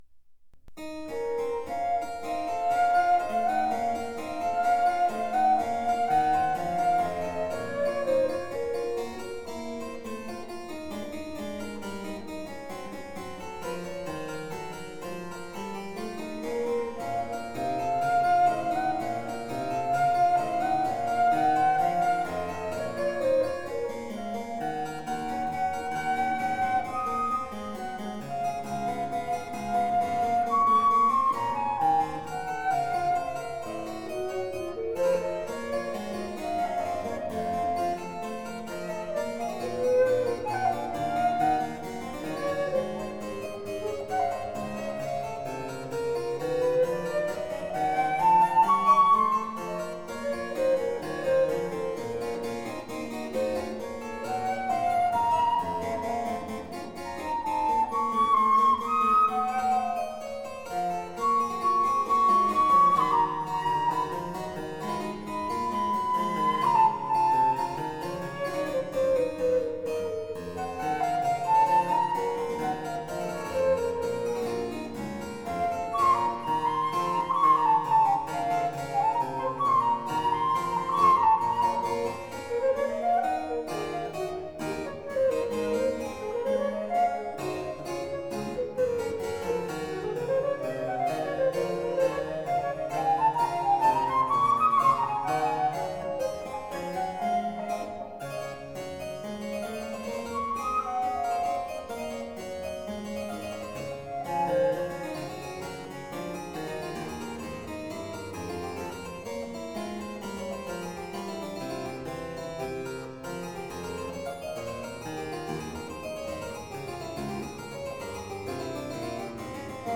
Johann Sebastian Bach (1685-1750) Sonate h-Moll BMW 1030 für Flöte und Cembalo Andante
Sonate h-Moll BMW 1030 für Flöte und Cembalo